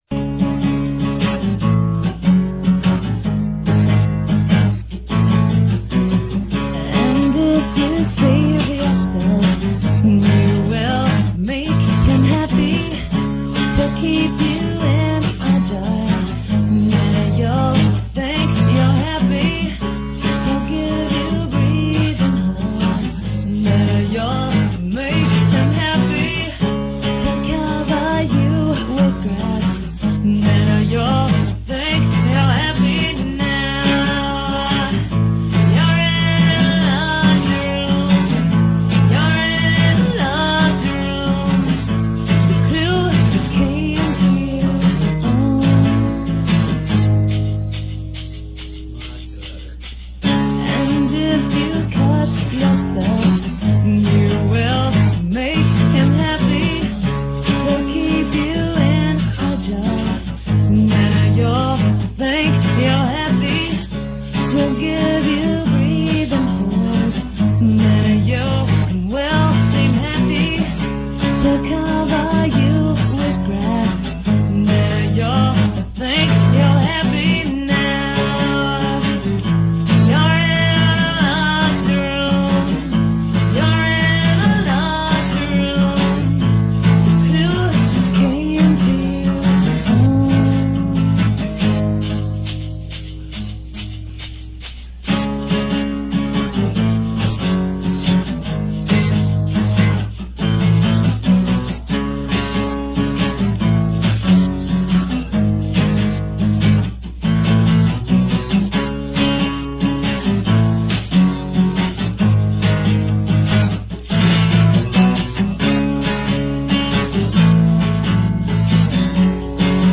this song was recorded in Chicago.